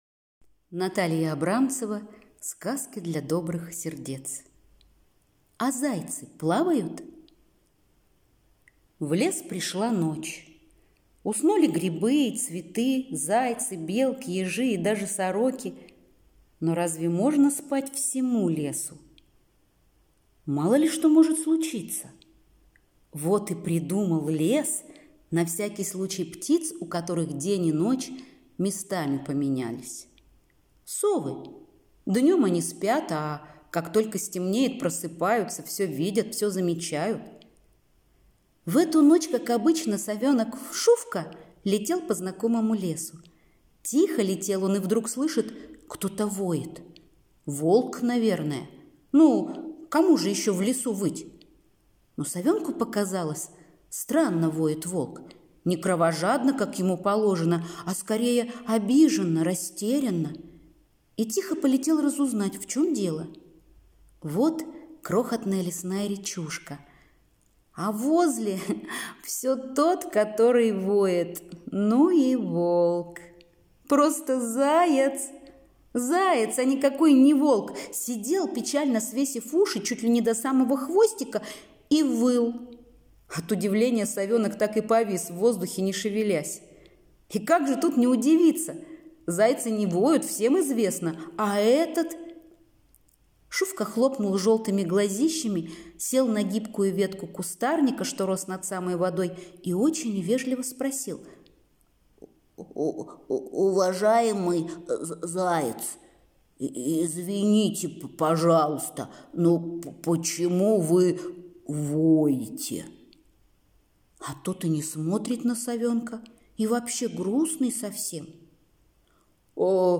А зайцы плавают? - аудиосказка Натальи Абрамцевой - слушать онлайн